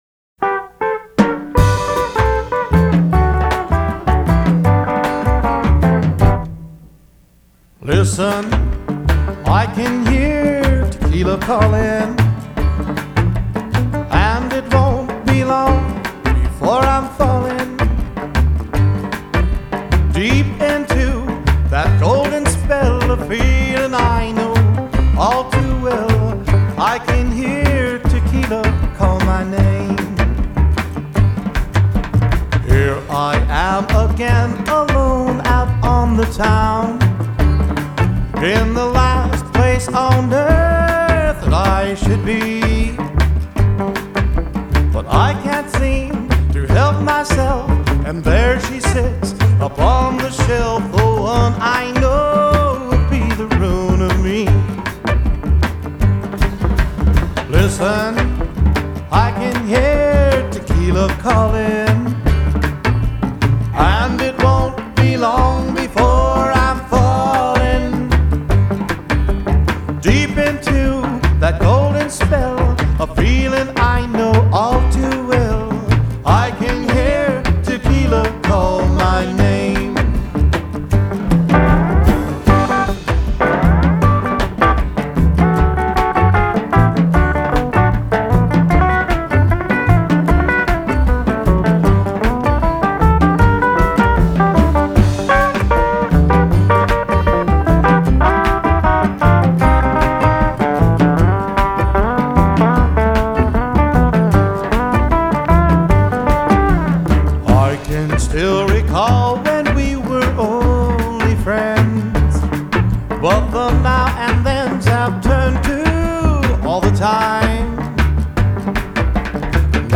relaxed, sincere vocals
guitar-steel duo
Rockabilly